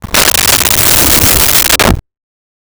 Creature Growl 01
Creature Growl 01.wav